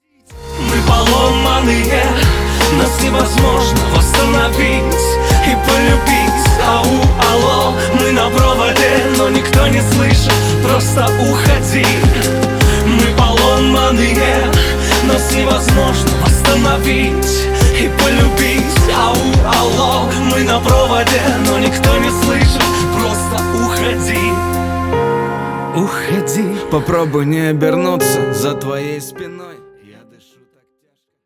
• Качество: 269, Stereo
лирика
грустные